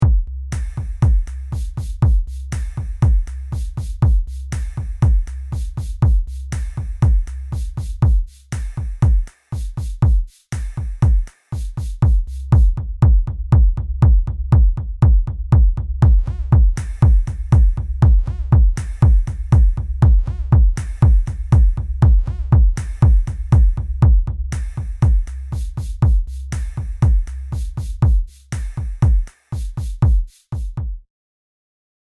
Bucle de Techno
pieza melodía repetitivo sintetizador